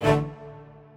strings6_32.ogg